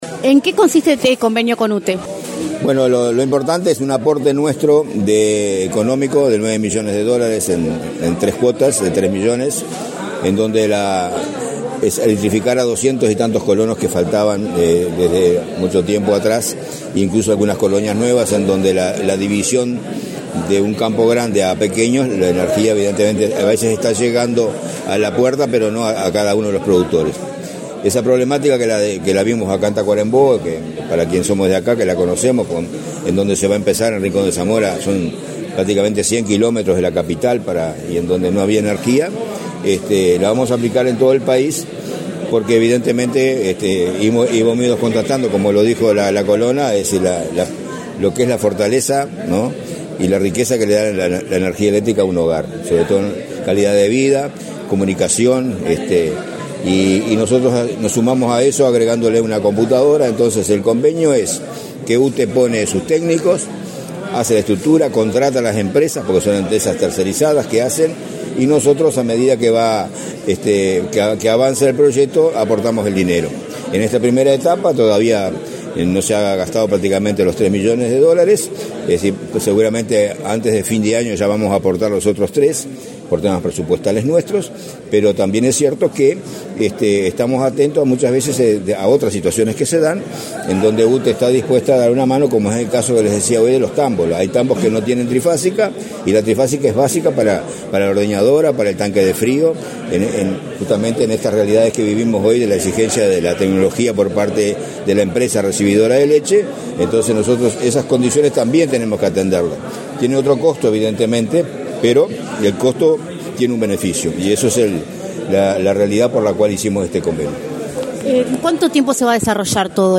Entrevista al presidente del INC, Julio César Cardozo
Tras el evento, el presidente del INC, Julio César Cardozo, realizó declaraciones a Comunicación Presidencial.